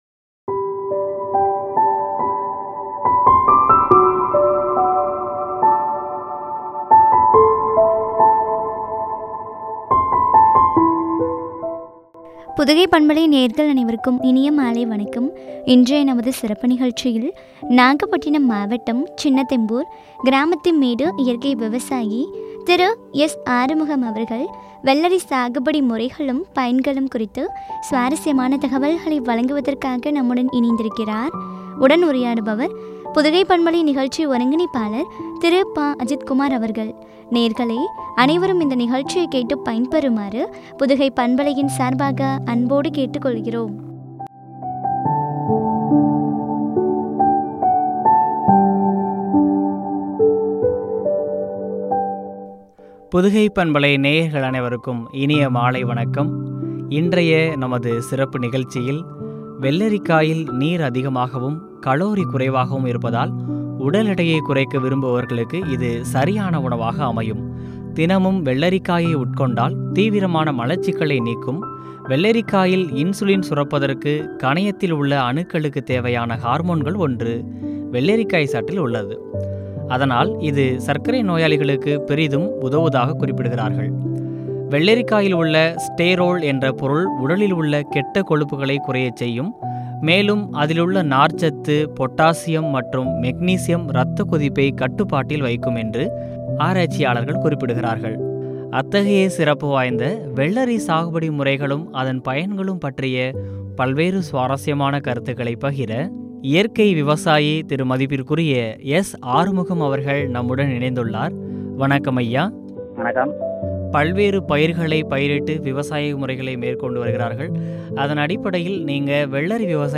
பயன்களும்” குறித்து வழங்கிய உரையாடல்.